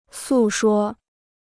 诉说 (sù shuō) Dictionnaire chinois gratuit